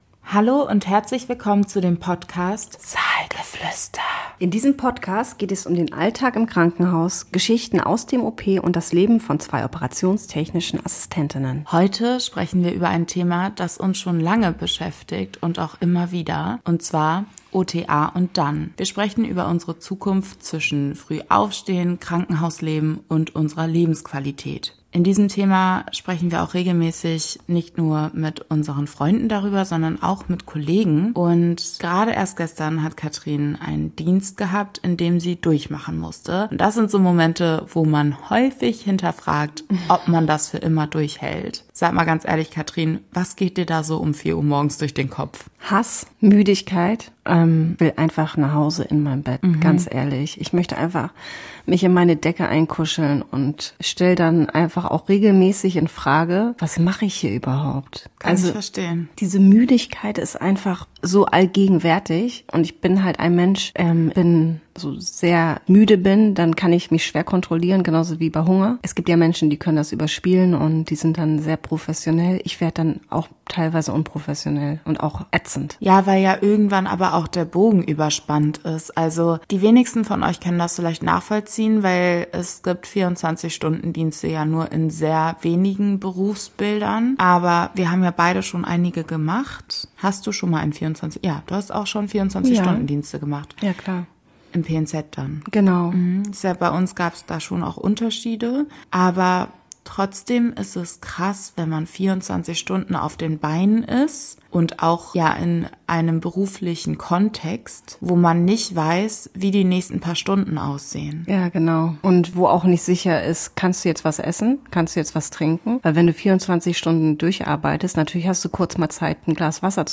Wie sieht die Zukunft für OTAs aus? Ein Gespräch über Arbeitsrealität, Fachkräftemangel und neue Karrierewege – von Weiterbildungen bis hin zu Studienmöglichkeiten.